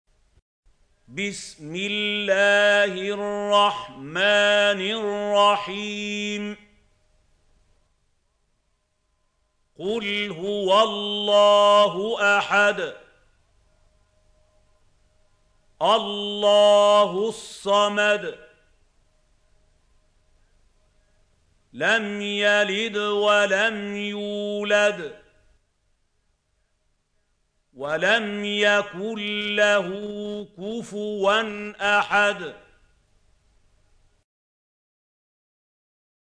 سورة الإخلاص | القارئ محمود خليل الحصري - المصحف المعلم